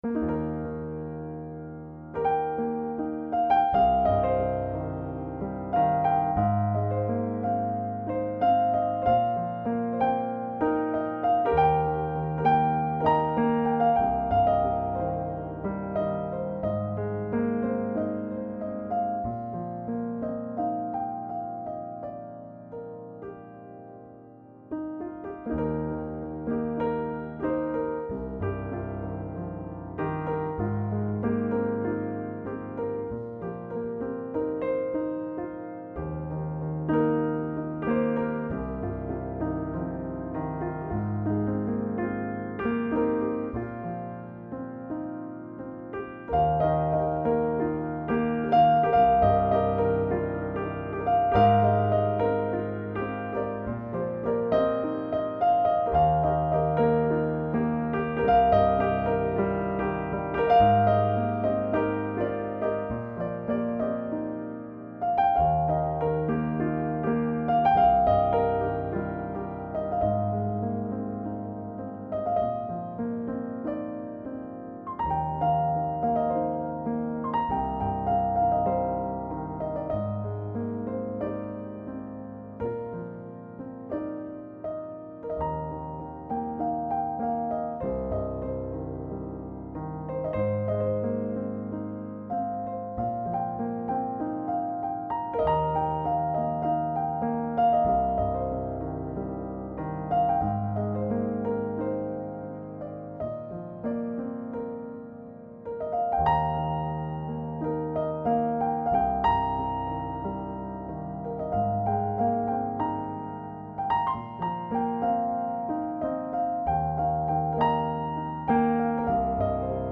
Finally I use Pianoteq for debugging before publishing my music.
• Introduction: This piece is modeled after some classical piano elements, making this piece with some vintage style.